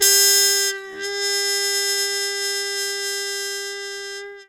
Index of /90_sSampleCDs/E-MU Formula 4000 Series Vol. 1 - Hip Hop Nation/Default Folder/Trumpet MuteFX X